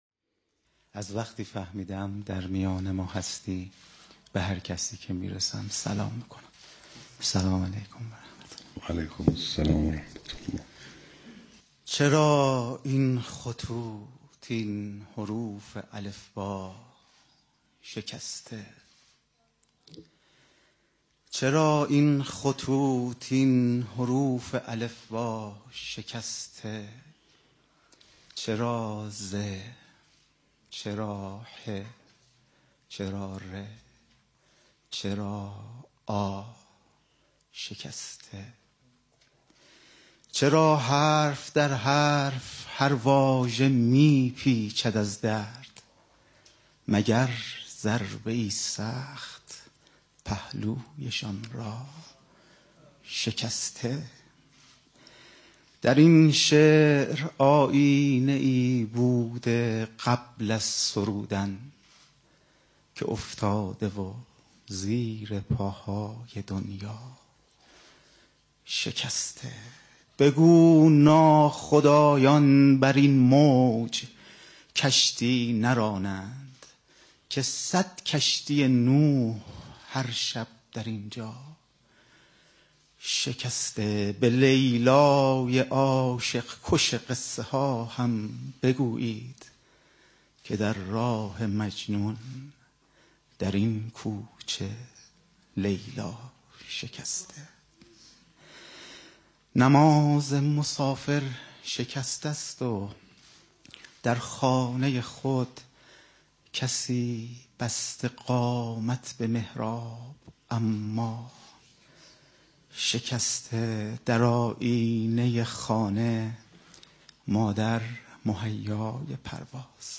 شعرخوانی | چرا این خطوط این حروف الفبا شکسته
محفل شاعران آئینی | حسینیه امام خمینی(ره)